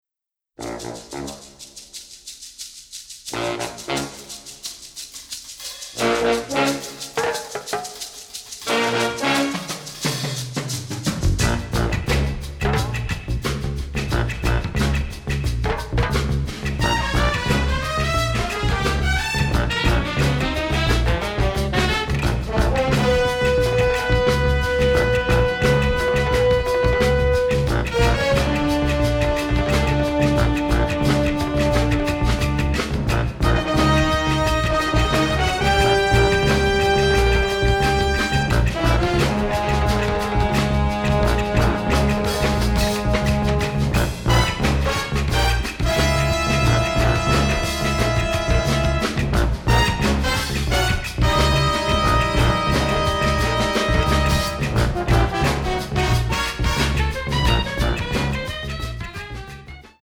funk/jazz/groove